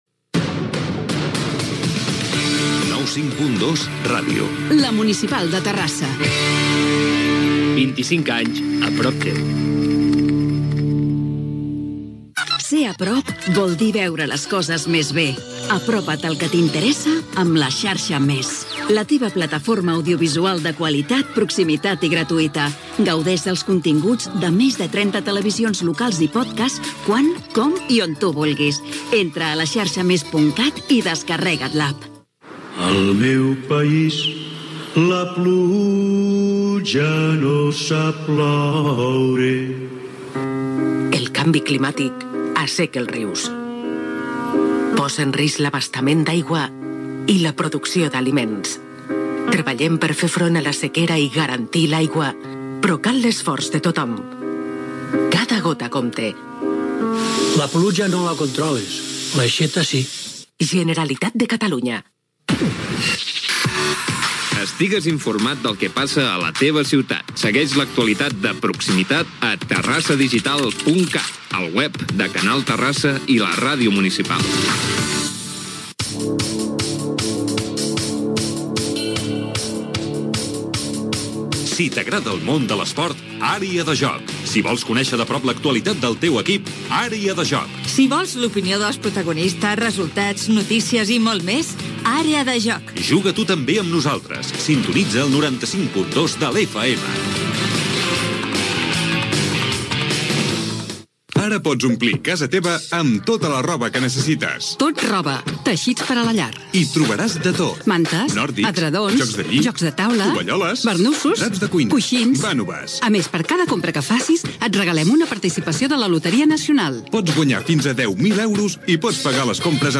Indicatiu de l'emissora, publicitat, promoció d'"Àrea de Joc", publicitat, careta del programa, data, sumari de continguts, titulars
Esportiu
FM